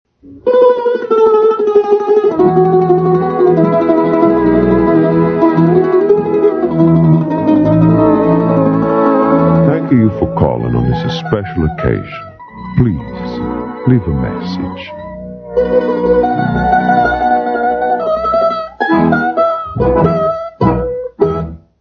Phonies Holiday Telephone Answering Machine Messages